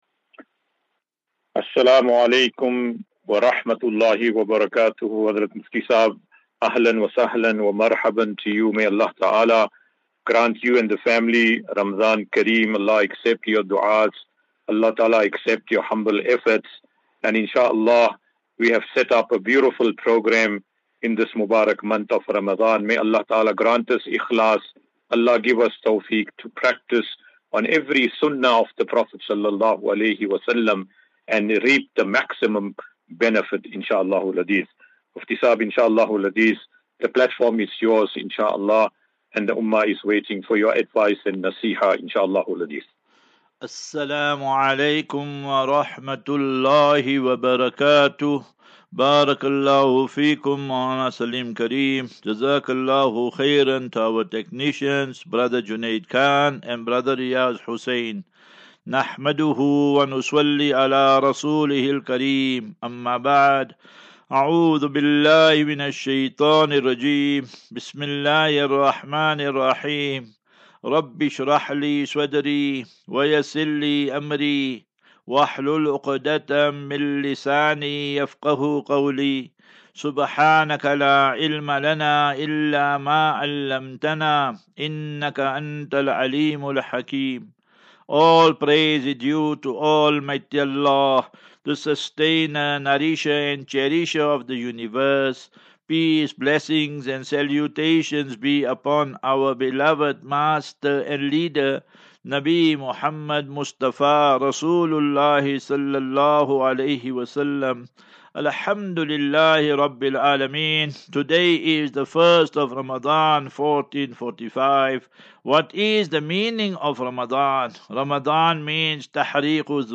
As Safinatu Ilal Jannah Naseeha and Q and A 12 Mar 12 March 2024.